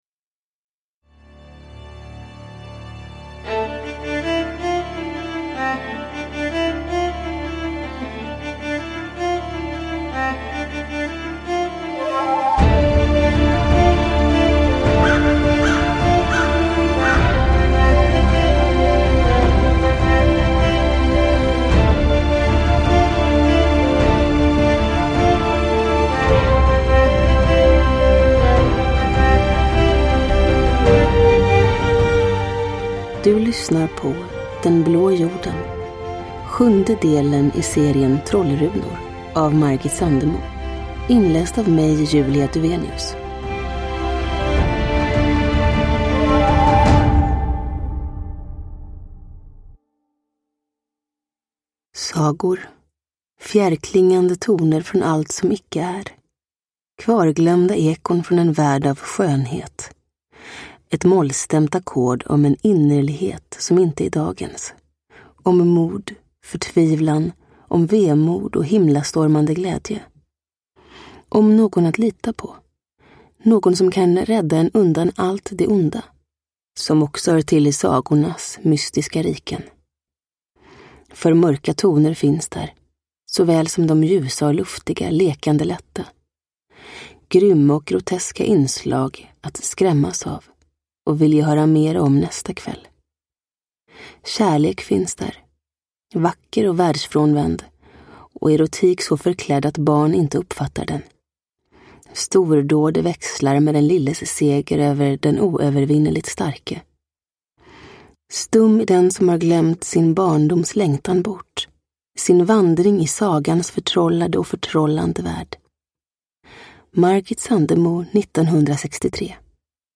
Den blå jorden – Ljudbok – Laddas ner
Uppläsare: Julia Dufvenius